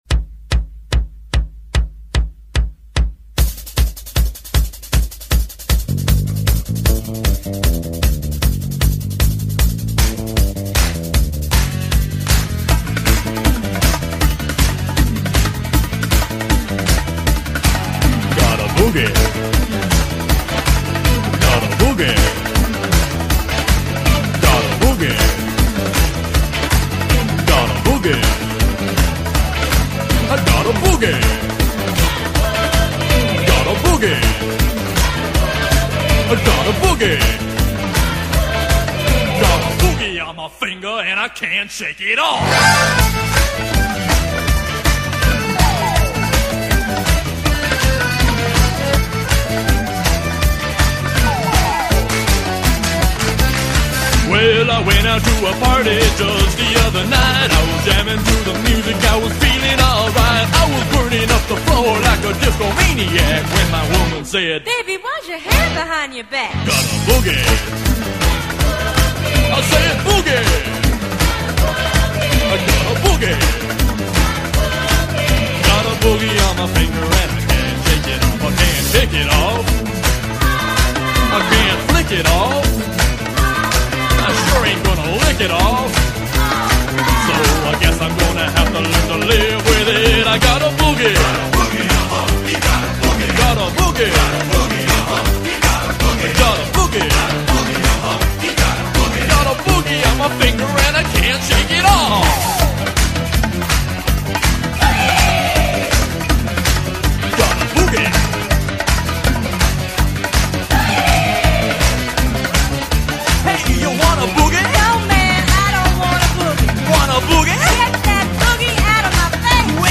The audio input on my computer blew out so I had to improvise (I'm using a microphone input, which has different impedance etc). The sound quality is really bad, and I apologize.